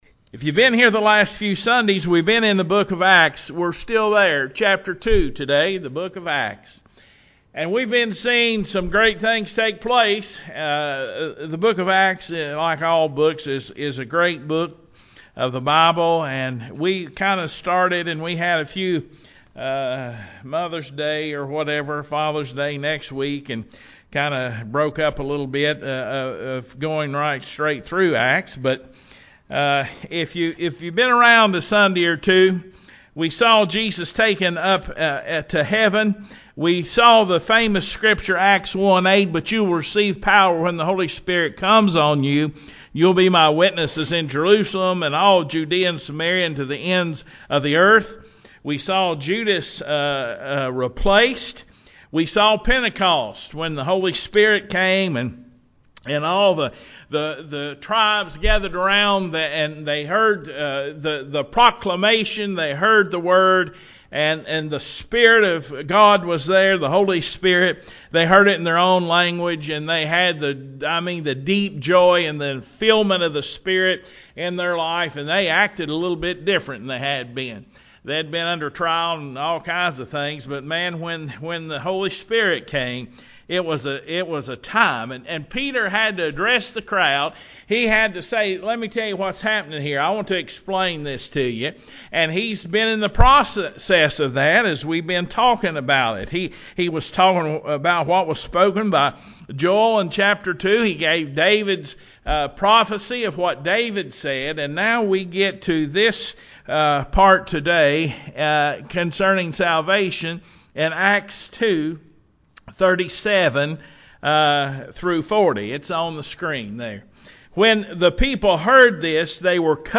060919-sermon-CD.mp3